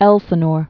(ĕlsə-nôr)